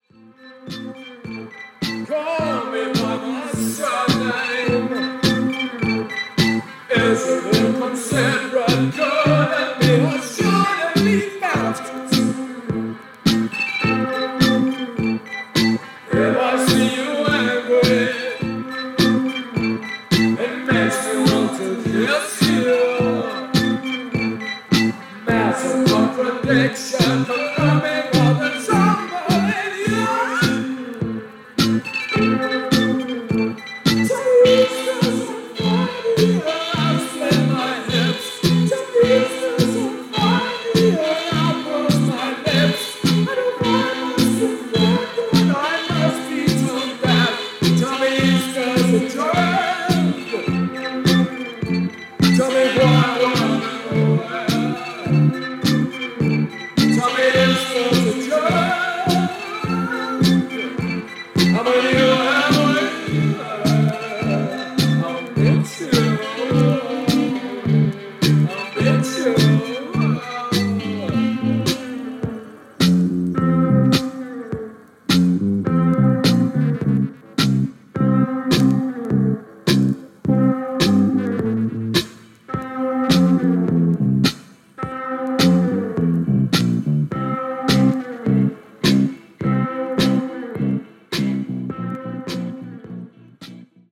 Post-Punk-New Wave